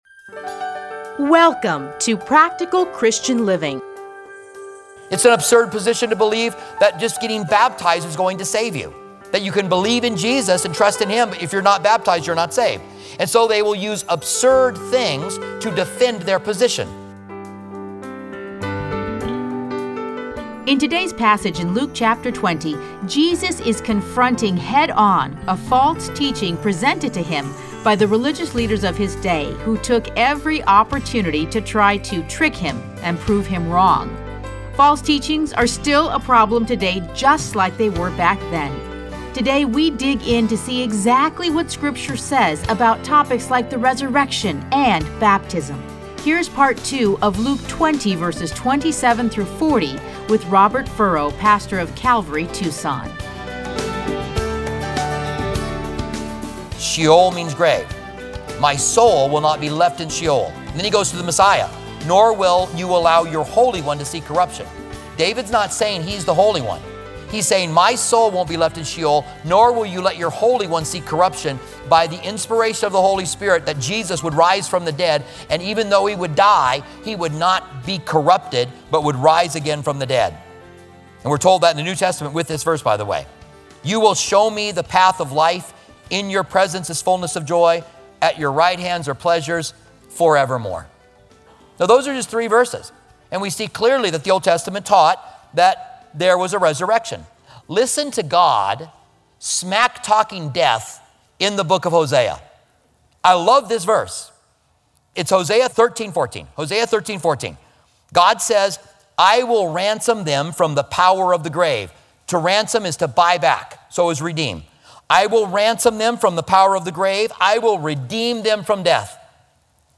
Listen to a teaching from Luke 20:27-40.